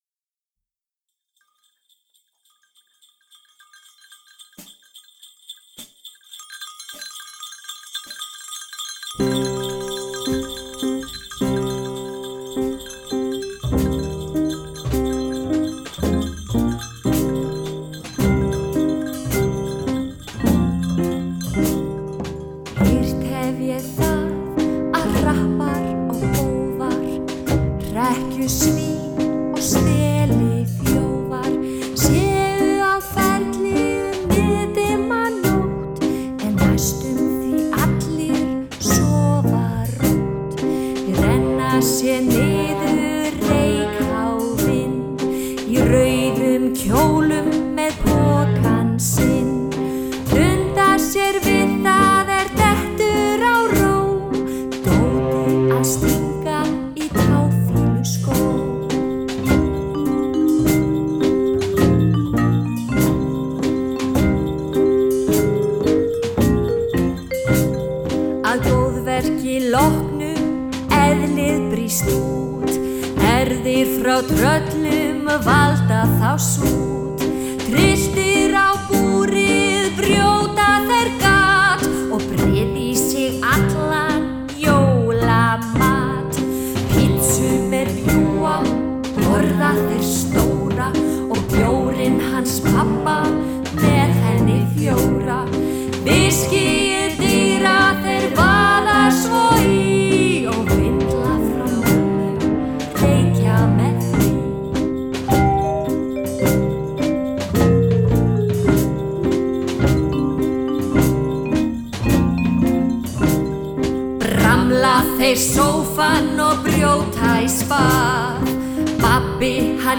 Söngur